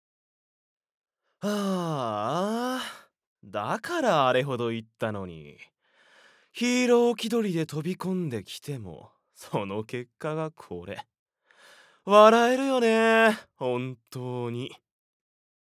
ボイスサンプル
皮肉屋の悪党